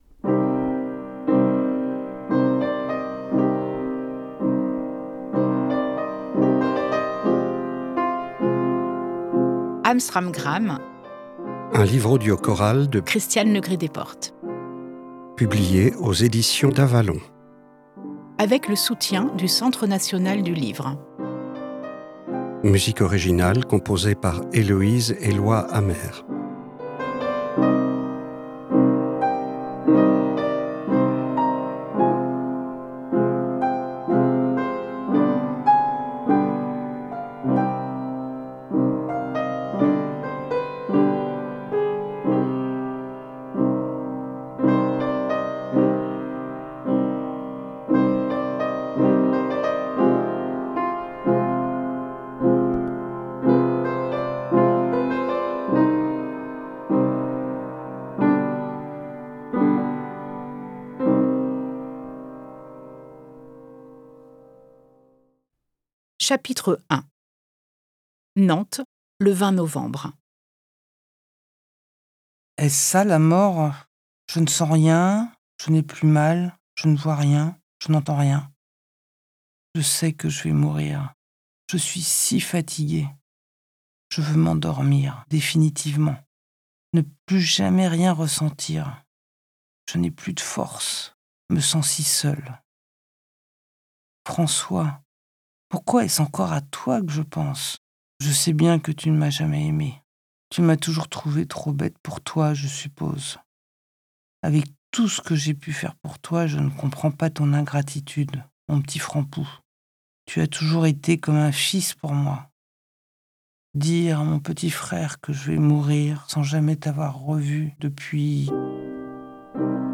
Âme Stram Gram - un roman bouleversant, d'une grande intensité émotionnelle.